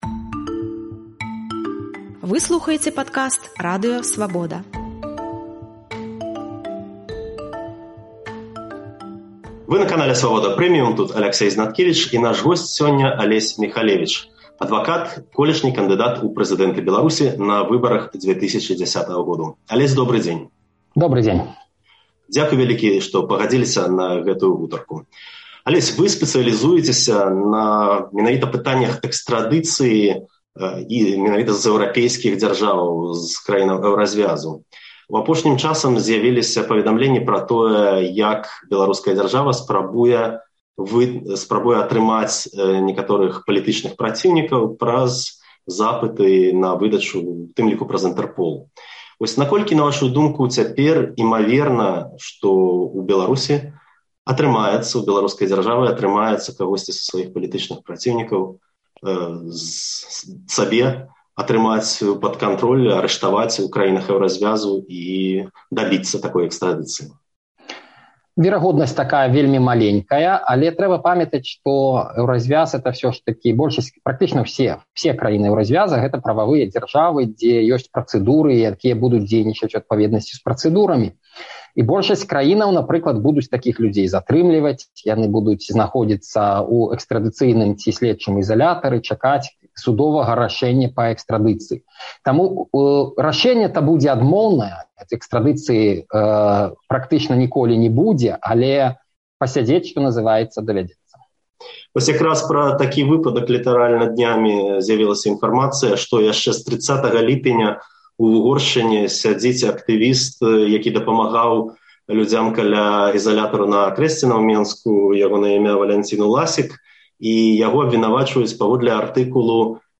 Адвакат і колішні кандыдат у прэзыдэнты Беларусі Алесь Міхалевіч, расказаў «Радыё Свабода», што варта рабіць тым беларусам, якія зьехалі з краіны, але адчуваюць рызыку, што можа прыйсьці запыт на іх экстрадыцыю.